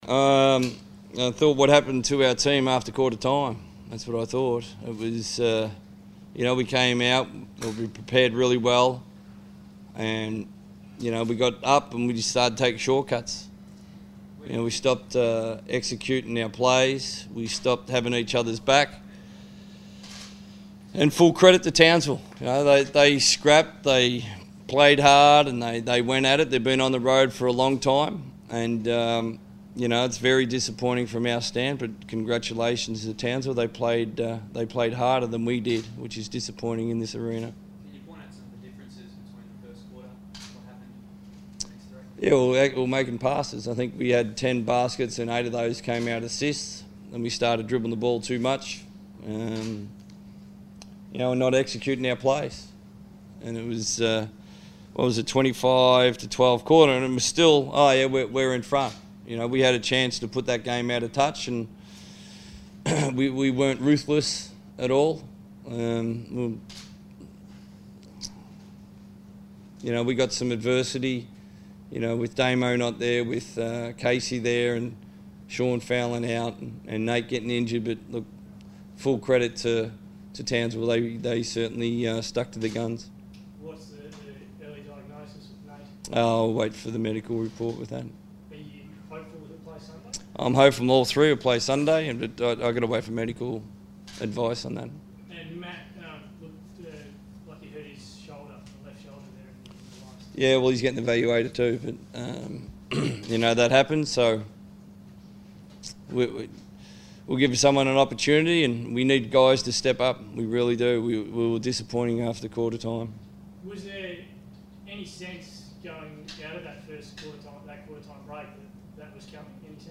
speak to the media following the Wildcats defeat versus the Townsville Crocodiles.